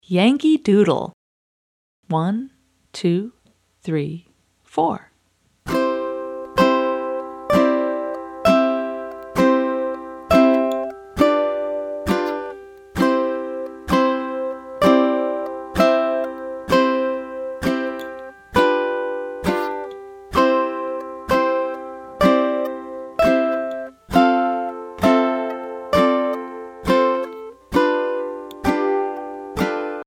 Voicing: Bass Guitar